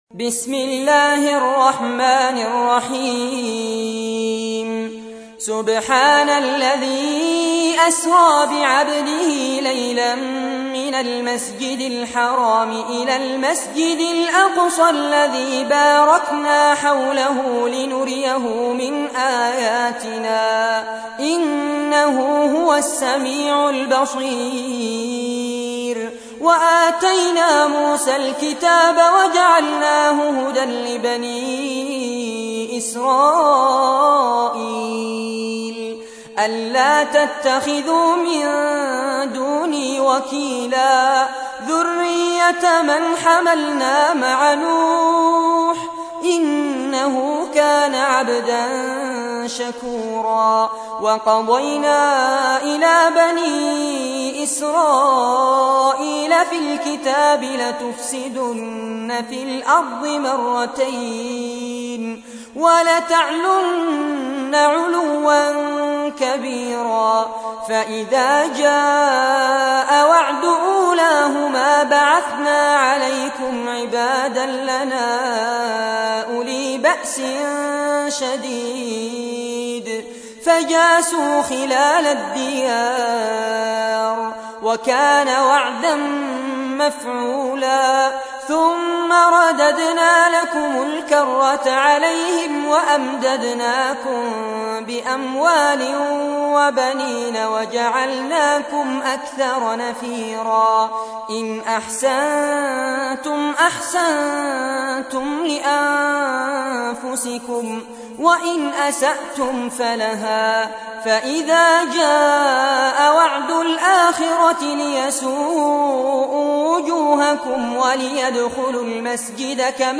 تحميل : 17. سورة الإسراء / القارئ فارس عباد / القرآن الكريم / موقع يا حسين